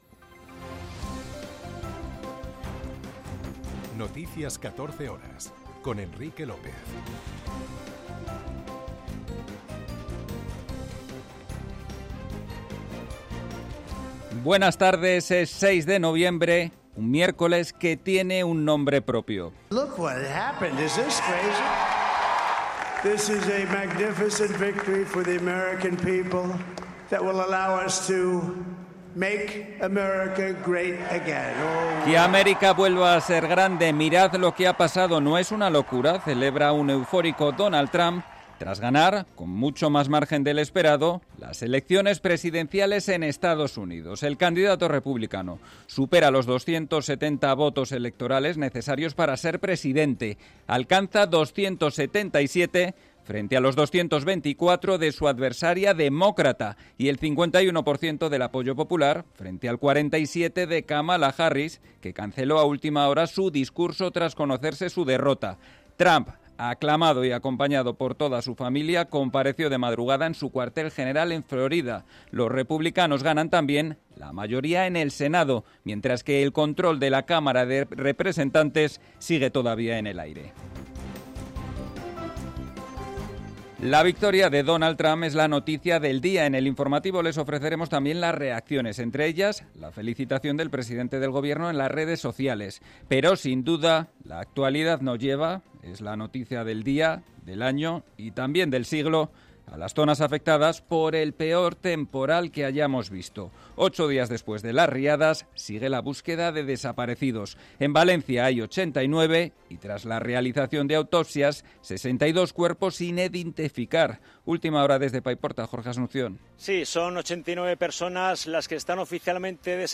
en España y en el mundo. 60 minutos de información diaria con los protagonistas del día y conexiones en directo en los puntos que a esa hora son noticia.